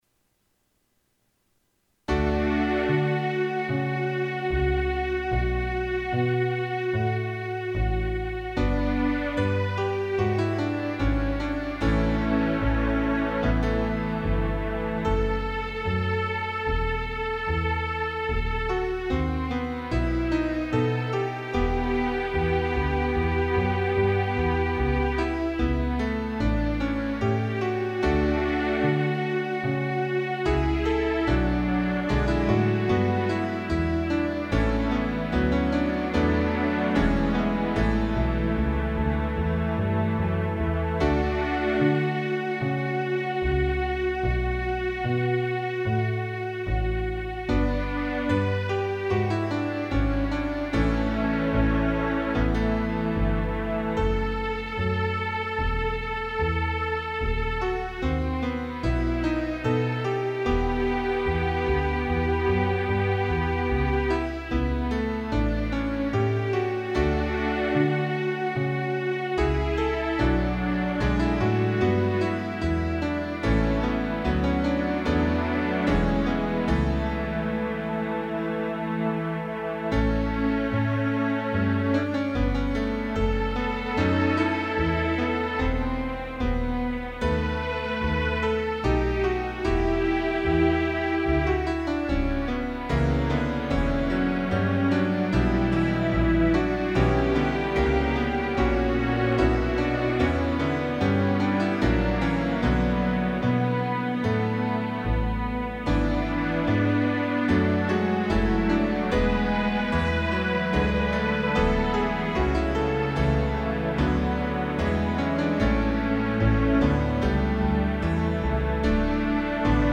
Pause/Resume MP3 playback The music was created from the Zel code below.
Notes destined for track-group top are copied to both the piano and strings tracks.